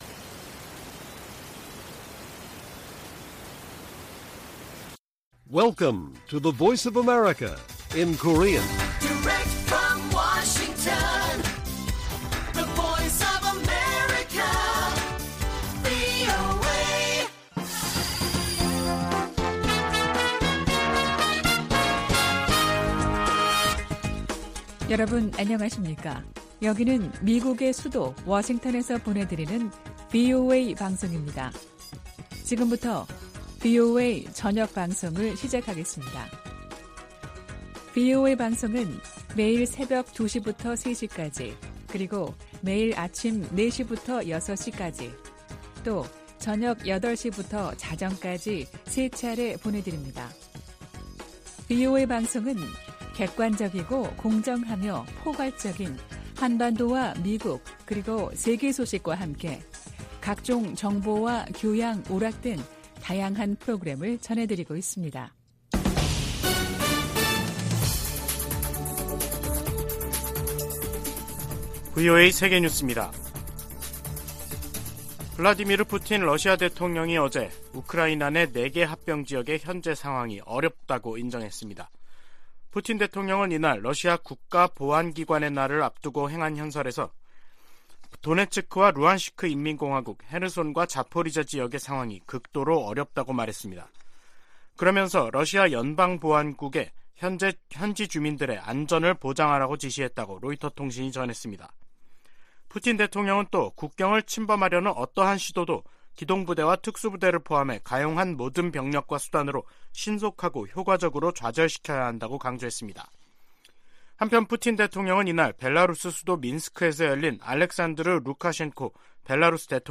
VOA 한국어 간판 뉴스 프로그램 '뉴스 투데이', 2022년 12월 20일 1부 방송입니다. 미 국무부는 북한이 정찰위성 시험이라고 주장한 최근 미사일 발사가 전 세계를 위협한다며, 외교로 문제를 해결하자고 촉구했습니다. 유엔은 북한의 최근 탄도미사일 발사와 관련해 한반도 긴장 고조 상황을 매우 우려한다며 북한에 즉각적인 대화 재개를 촉구했습니다.